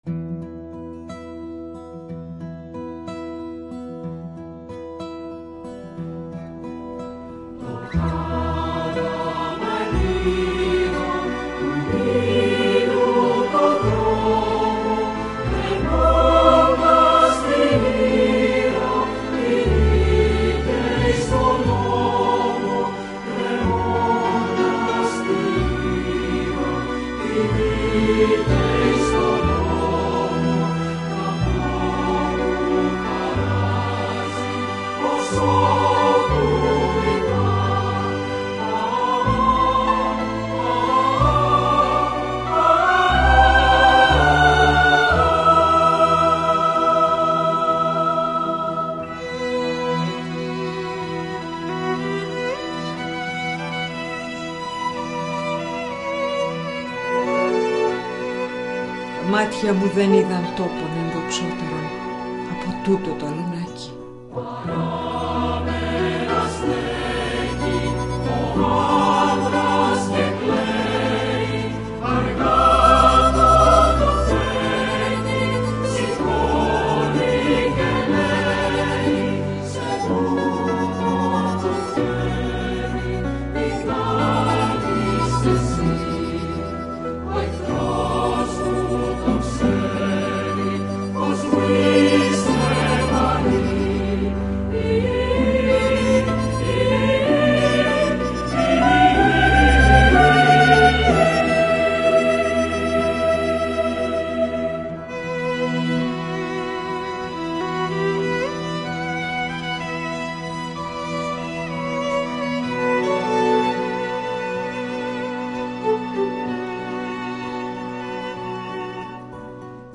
Χορικό